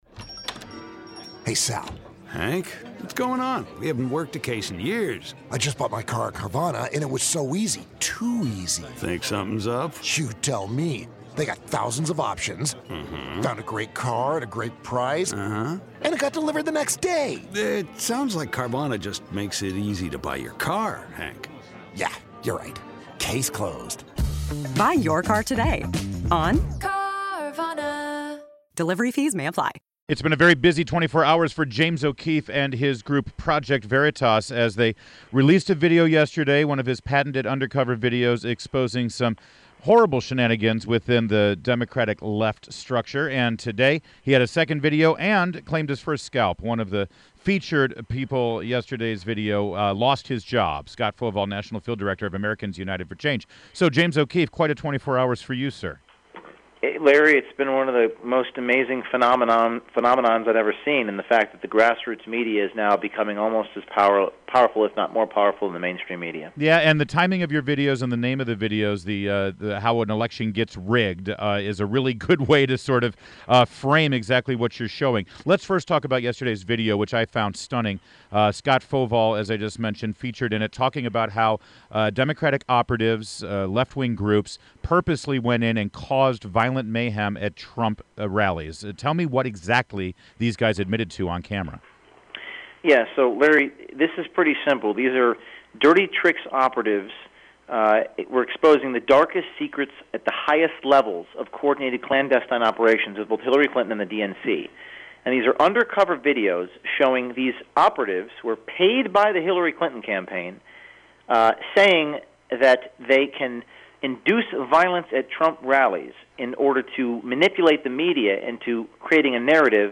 INTERVIEW — JAMES O’KEEFE – Project Veritas